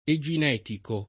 eginetico [ e J in $ tiko ]